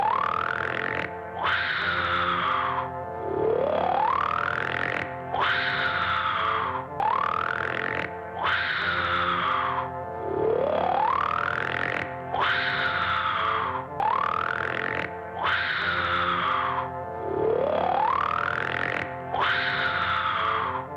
Cartoon Animal Snoring Sound Effect Free Download
Cartoon Animal Snoring